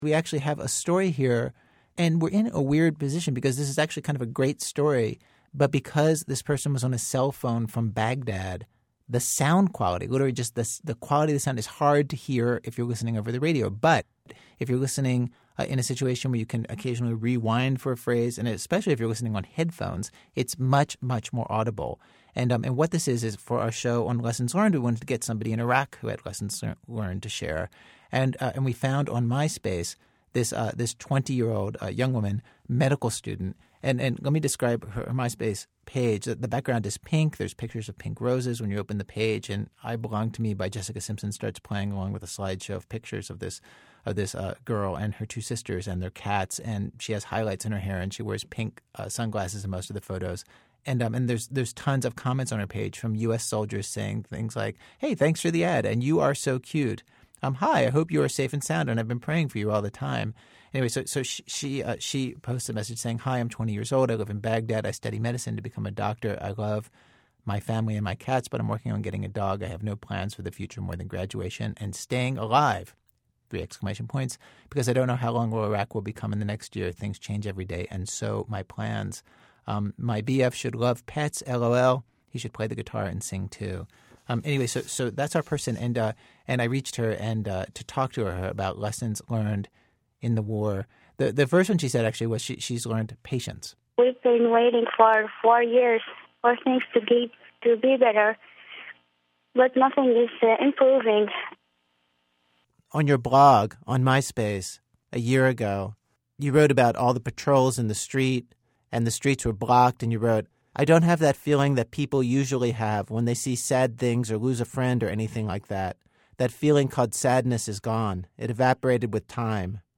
It's a phone call with a 20-year-old medical student in Baghdad, and the audio quality is a little too sketchy for broadcast, but not too terrible to listen to sitting at a computer or—even better—on headphones.
333_IraqiWoman.mp3